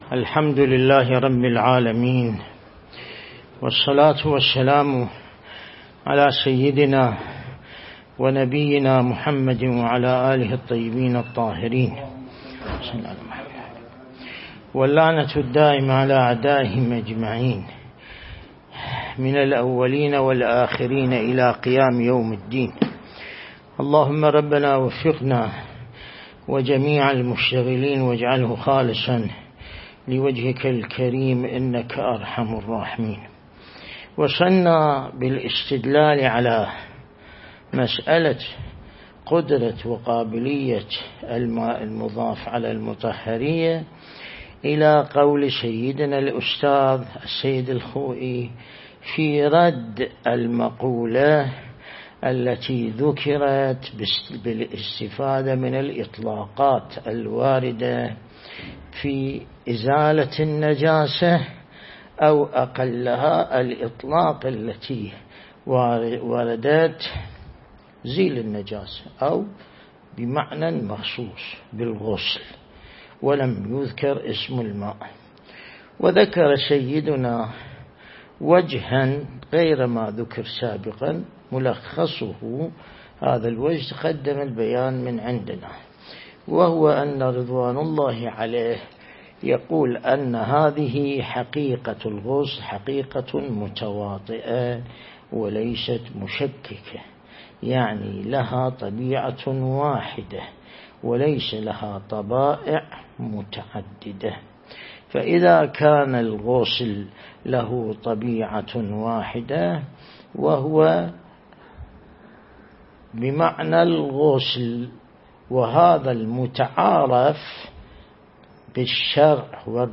درس (36) | الدرس الاستدلالي شرح بحث الطهارة من كتاب العروة الوثقى لسماحة آية الله السيد ياسين الموسوي(دام ظله)